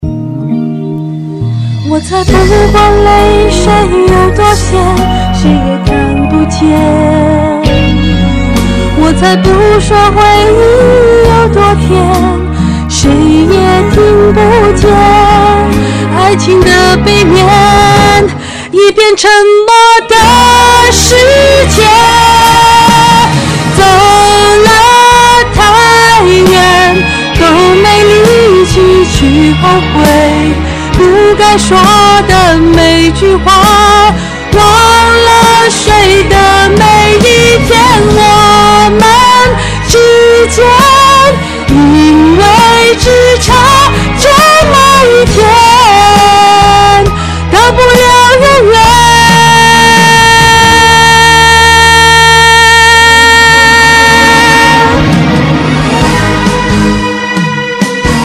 HELP: crackling noise when recording (C01U)
I am new to this recording hobby and just got this new Samson C01U mic. I am trying to record singing but my voice doesn't come out so clearly especially when the sound level is high.